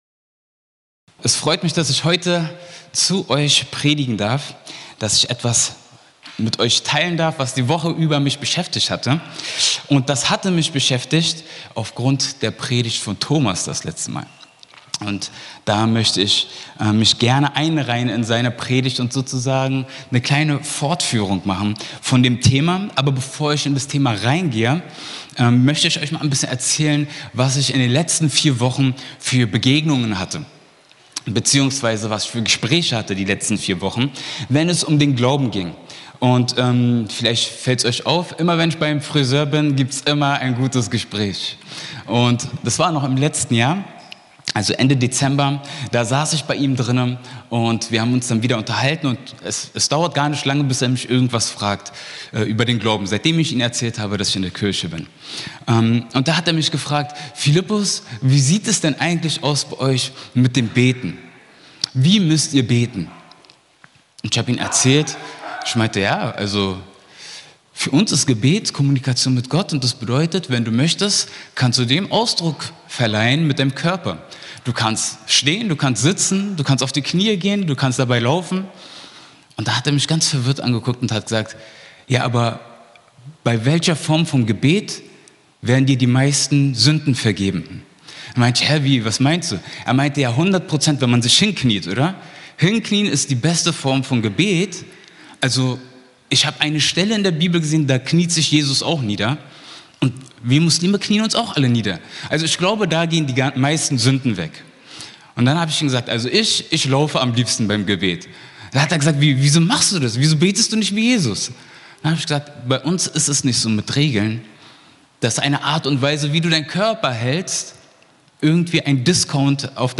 Gottesdienst vom 26.01.2025